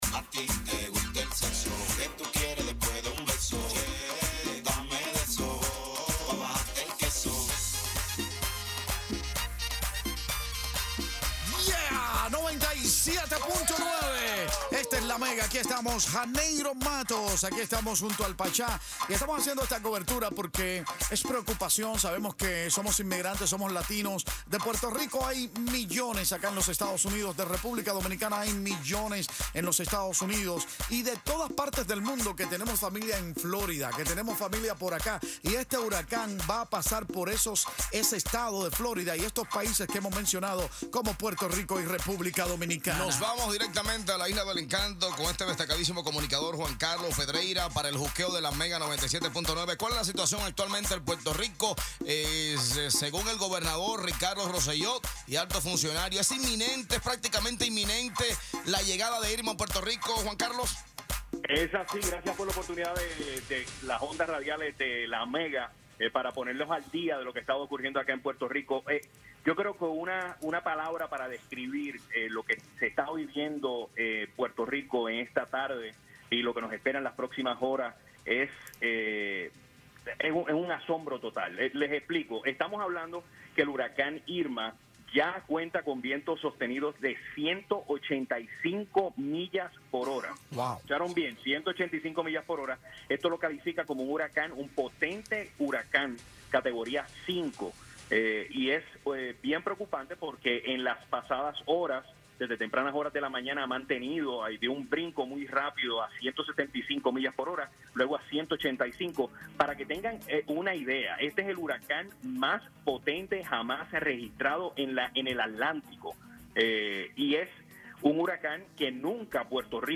REPORTAJE-SOBRE-EL-HURACAN-IRMA-EN-PUERTO-RICO.mp3